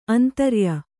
♪ antarya